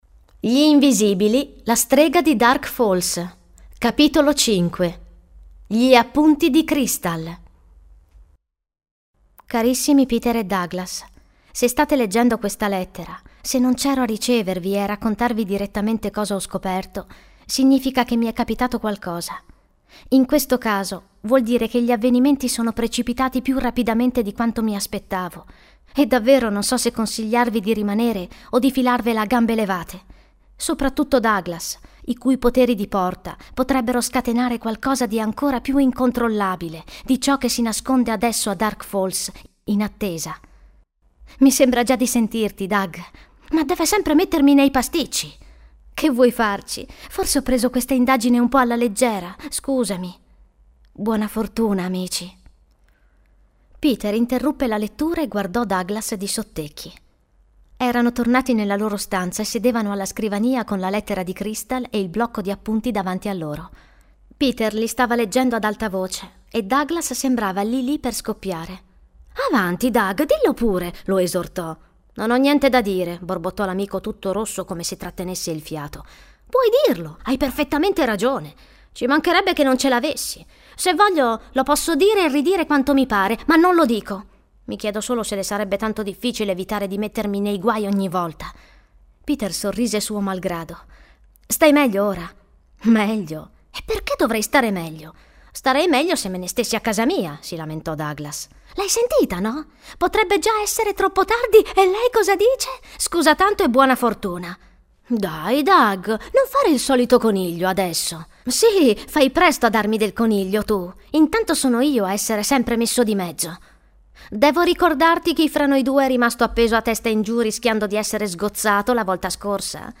Ascolta il primo capitolo letto dall’attrice